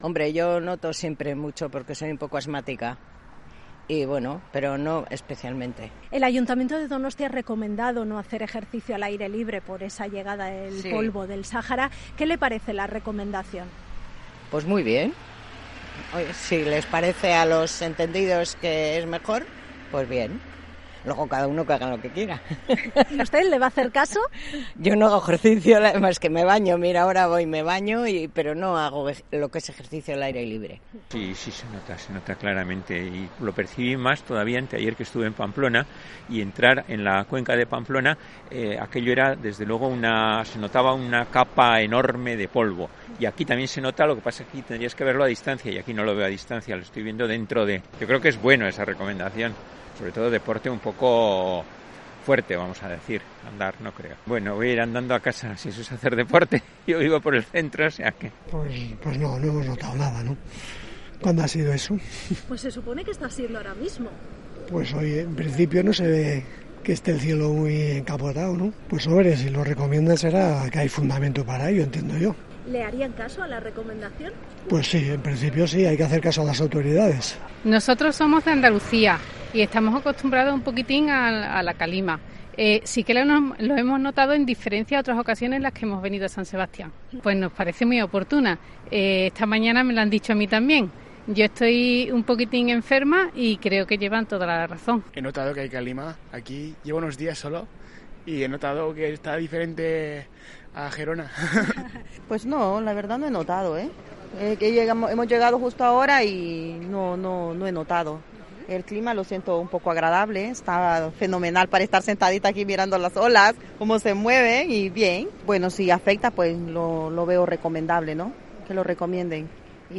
Tras la recomendación de no hacer ejercicio al aire libre, lanzada por los ayuntamientos de Donostia y Bilbao, hemos salido a la calle para comprobar si se cumple